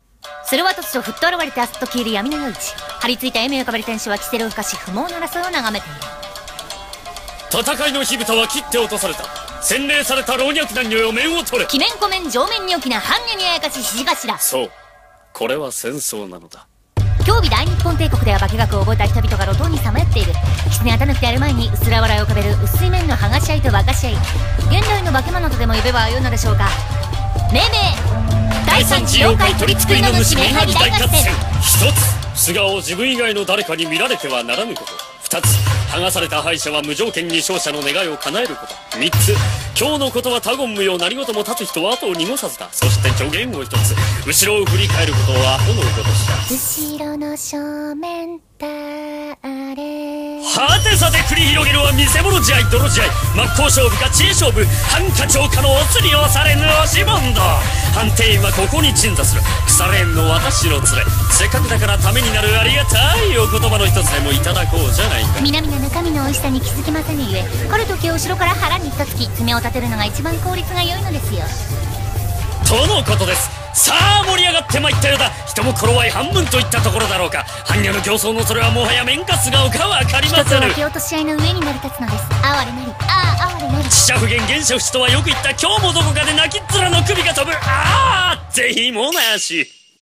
CM風声劇